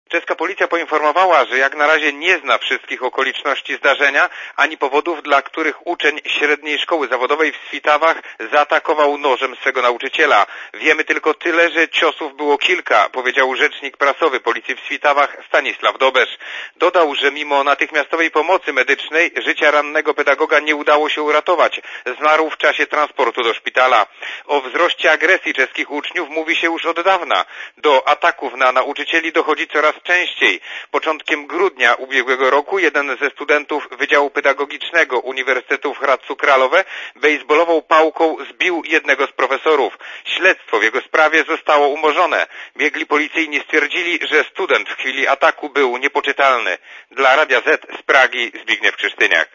Posłuchaj relacji korespondenta Radia Zet (192kB)